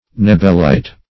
Search Result for " knebelite" : The Collaborative International Dictionary of English v.0.48: Knebelite \Kne"bel*ite\, n. [From Major von Knebel.]